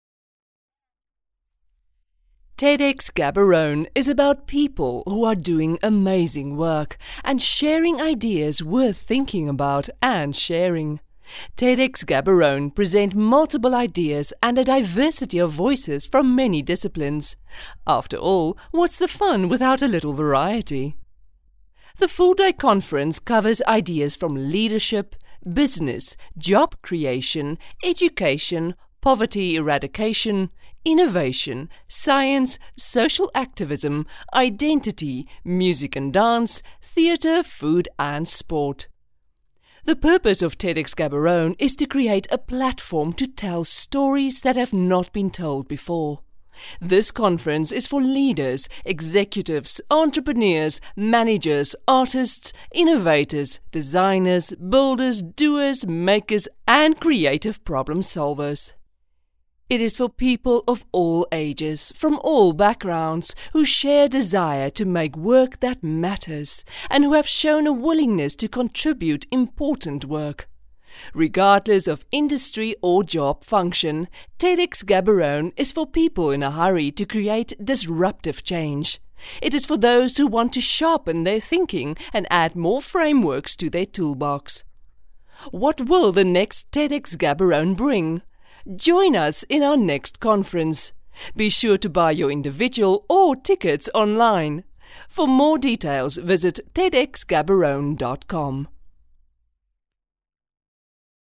Female
Approachable, Authoritative, Confident, Conversational, Corporate, Gravitas, Natural, Reassuring, Warm
South African
My voice has been described as warm, earthy, sensual, clear and authoritative.